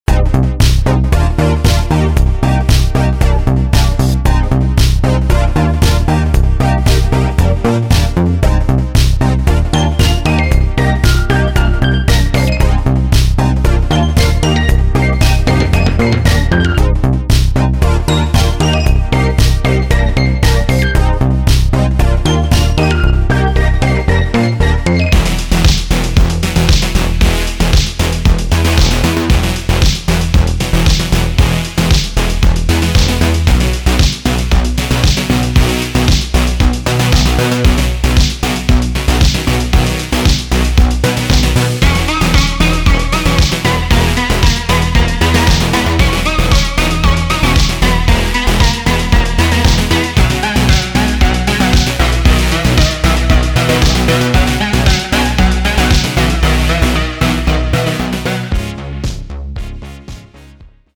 这些片段涵盖了该类型的典型元素，包括鼓机节拍、模拟合成器贝斯、和弦和旋律。
它以其强烈的合成器声音、快节奏和欢快的旋律而闻名。